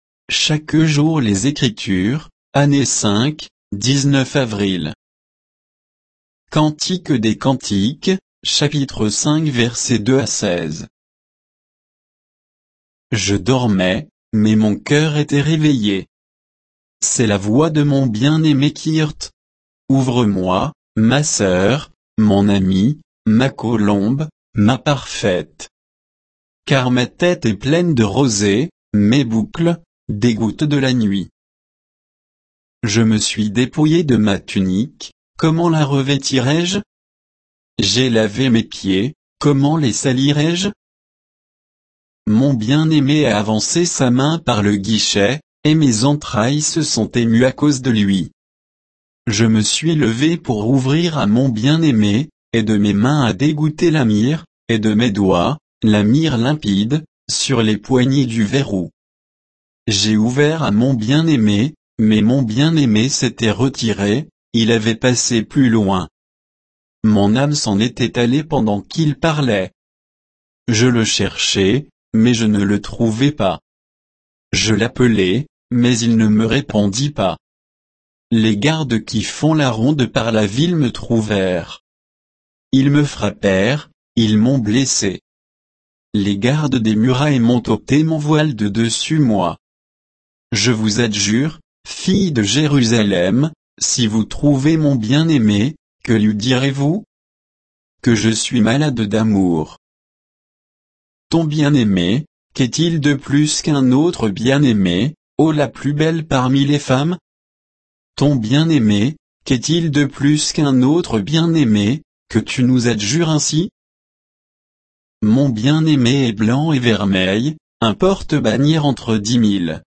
Méditation quoditienne de Chaque jour les Écritures sur Cantique des cantiques 5